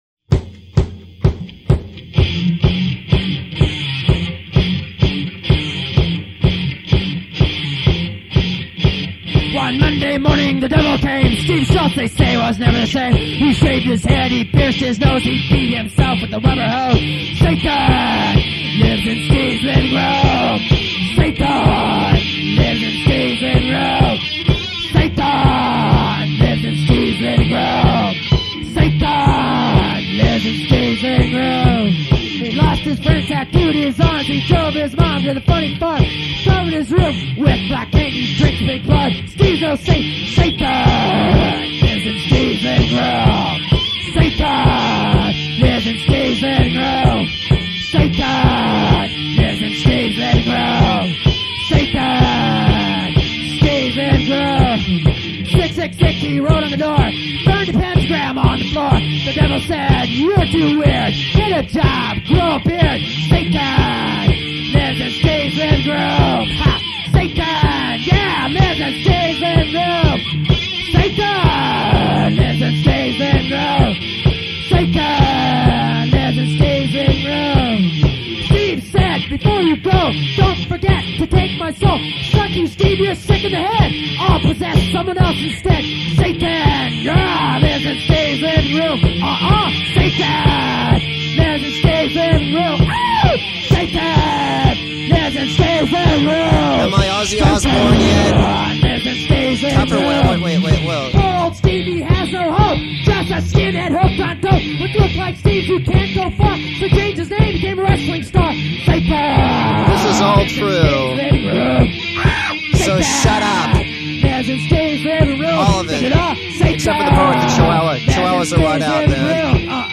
recorded on a Tascam Porta-One four track